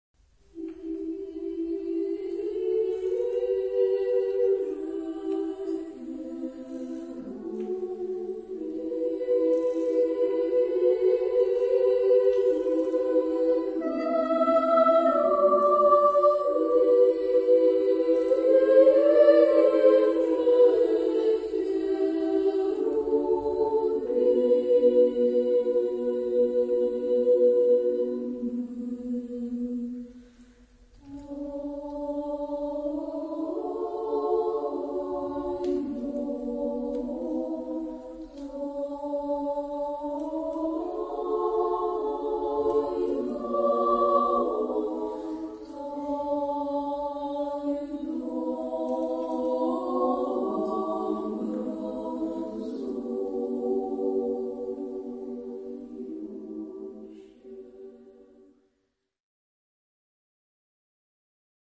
Liturgy for treble voices.- Cherubic Hymn (Izhe kheruvimi), von Alexander Dmitriyevich Kastalsky.
liturgische Hymne (orthodox)
feierlich ; fromm
Frauenchor